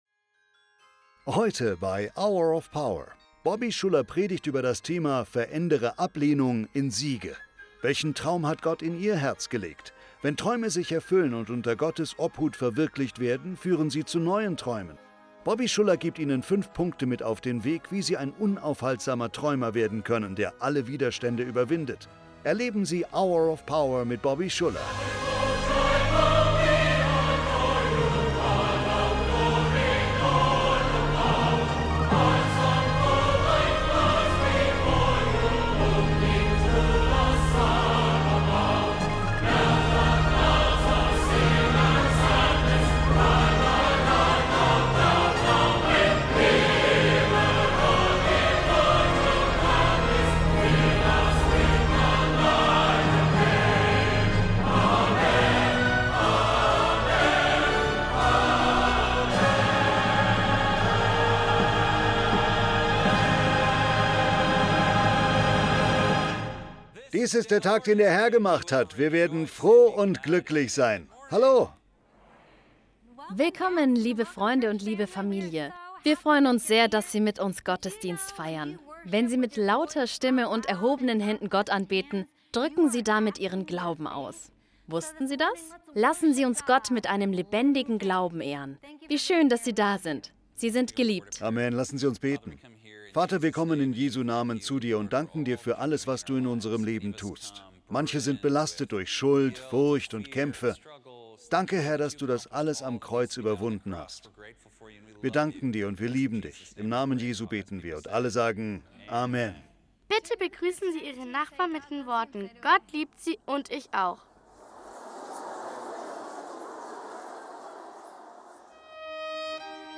Predigt